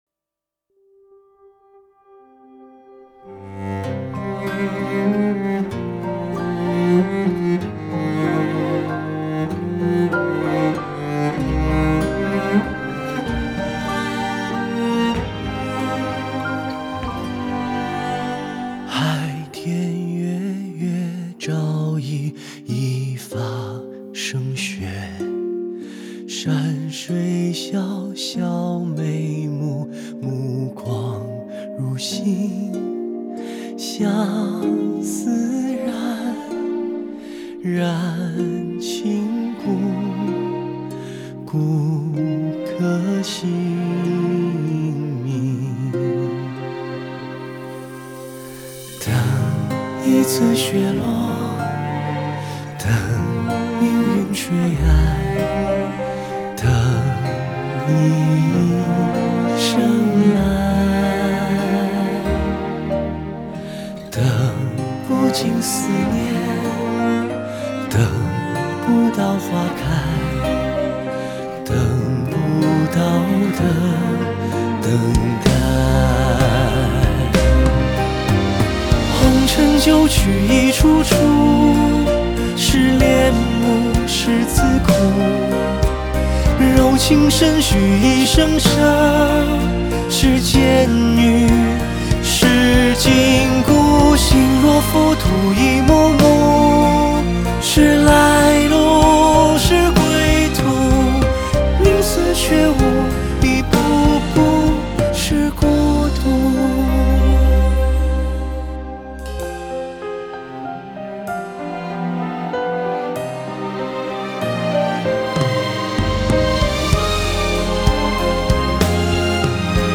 弦乐
大提琴
和音